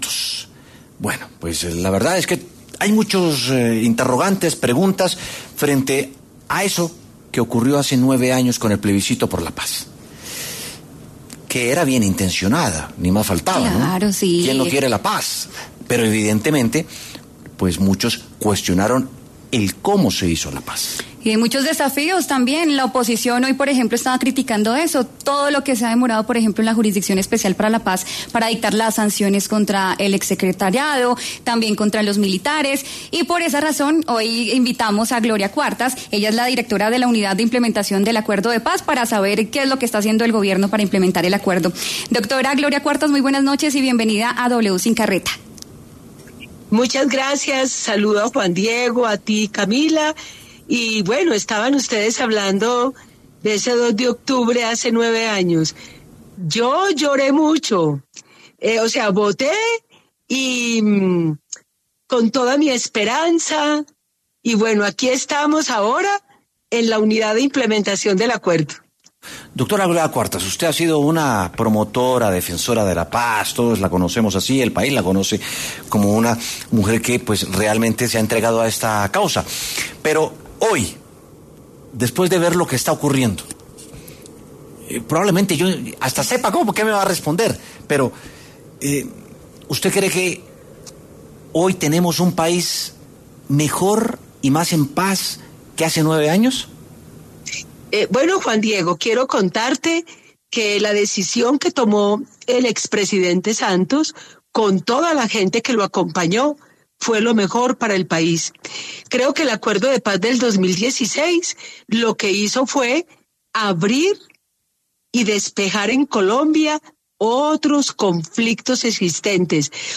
Nueve años después, Gloria Cuartas, la directora de la Unidad de Implementación del Acuerdo de Paz (UIAP), pasó por los micrófonos de W Sin Carreta para hablar sobre los avances y obstáculos que ha tenido este proyecto en el país.